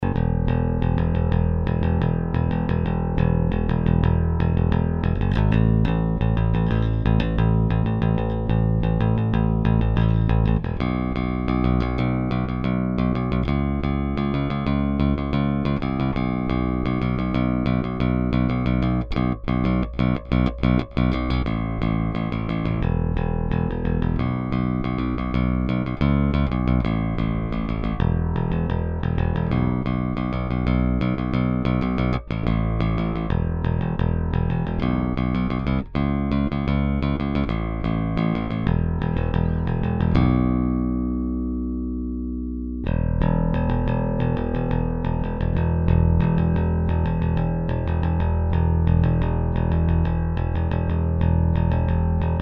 Bass recording